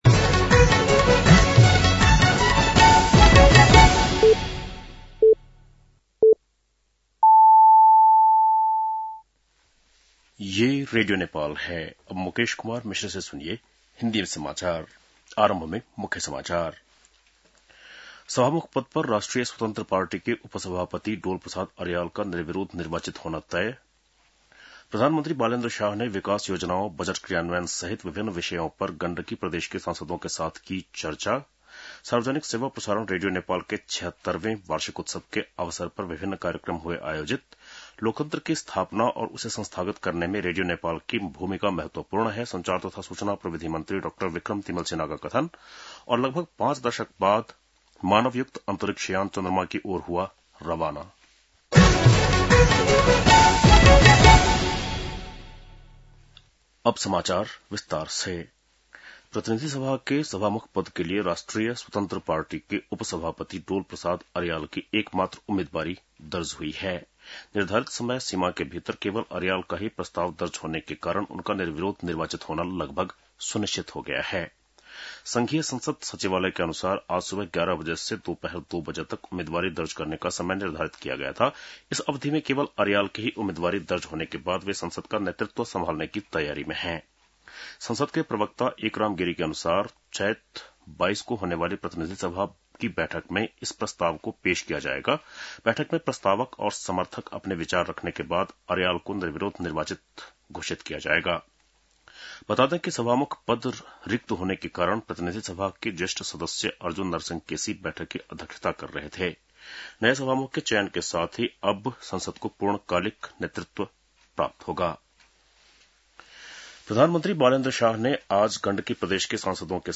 बेलुकी १० बजेको हिन्दी समाचार : २० चैत , २०८२